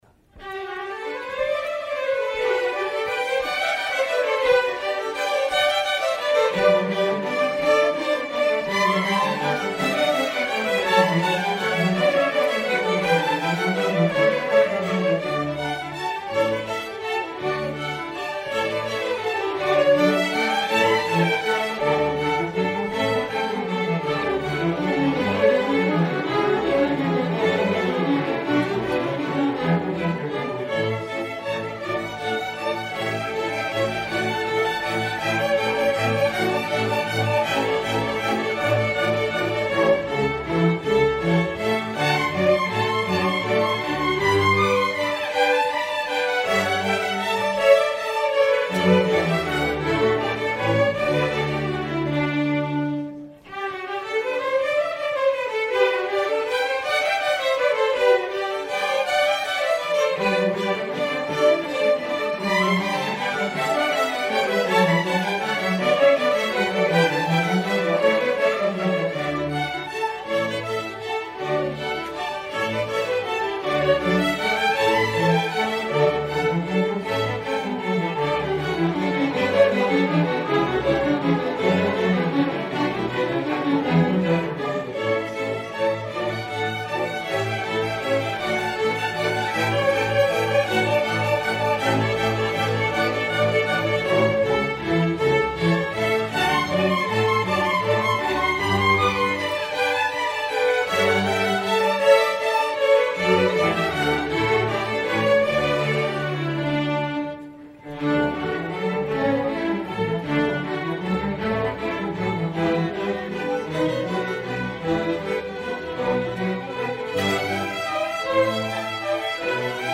Fall 2019 Concert
Queen City Community Orchestra